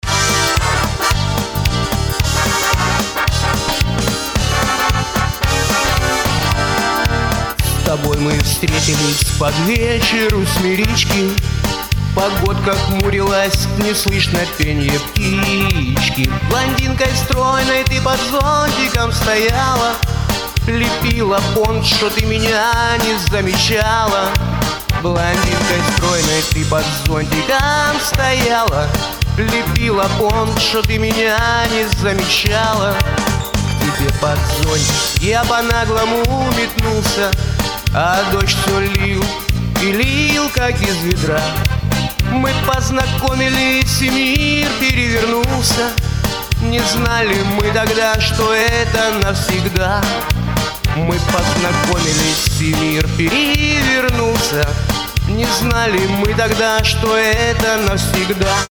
• Качество: 320, Stereo
шансон
авторская песня